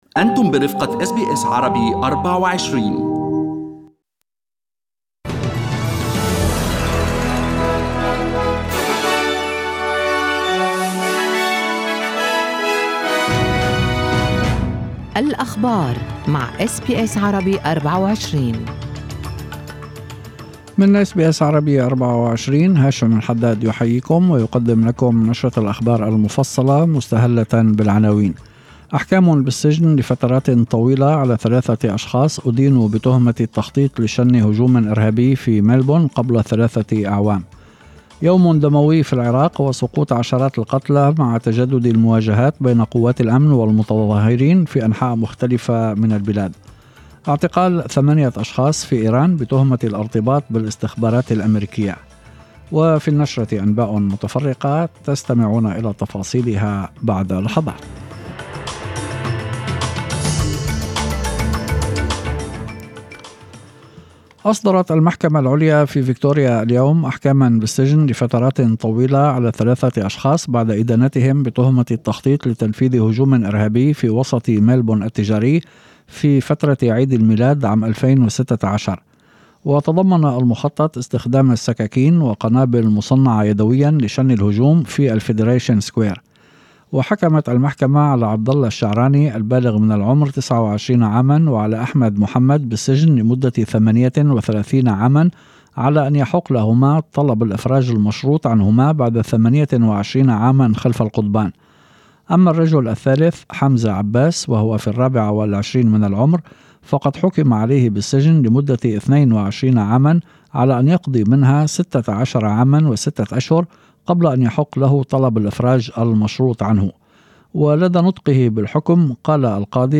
أخبار المساء: الحكم على "دواعش ملبورن" بالسجن 28 عاما